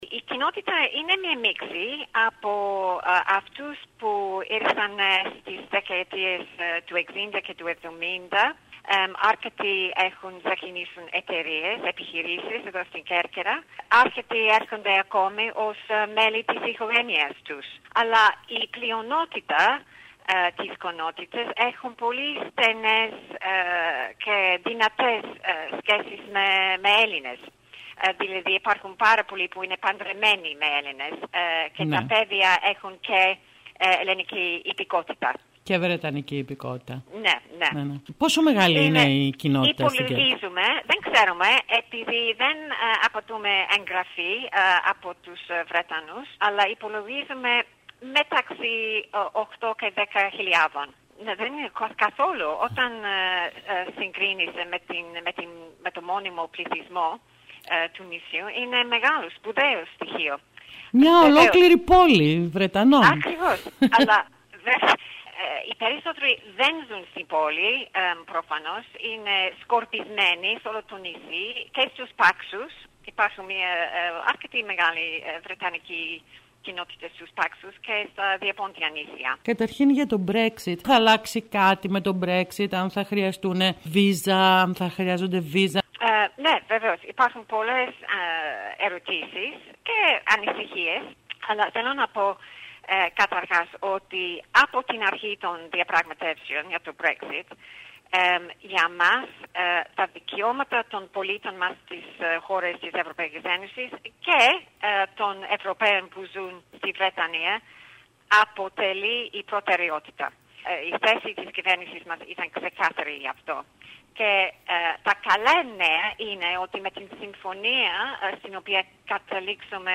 H πρέσβης της Βρετανίας στη συνέντευξη που έδωσε στην ΕΡΤ Κέρκυρας είπε ότι η συμφωνία για το BREXIT εξασφαλίζει την συνέχιση της ζωής των Βρετανών στις χώρες της Ευρωπαϊκής Ένωσης και το αντίστροφο – των Ευρωπαίων που ζουν στην Βρετανία- με τα ίδια δικαιώματα.